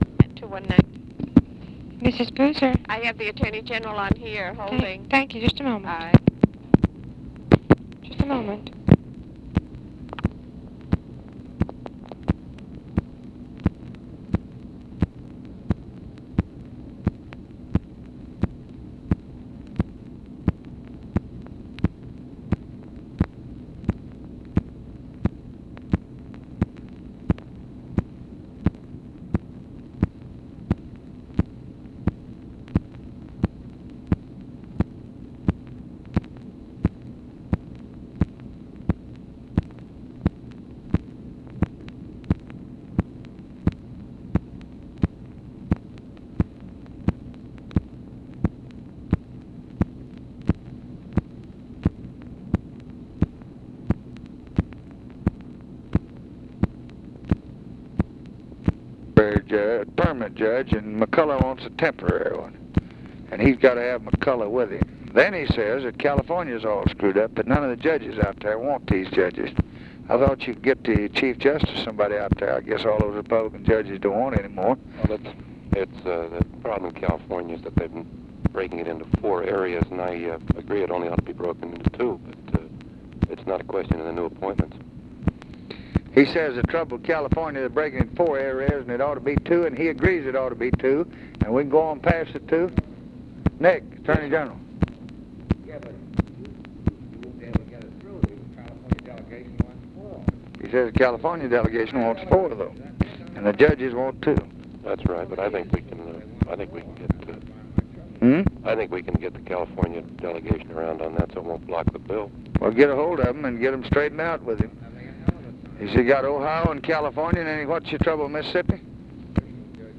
Conversation with NICHOLAS KATZENBACH, OFFICE CONVERSATION and EMANUEL CELLER, August 26, 1965
Secret White House Tapes